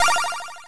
synth4.wav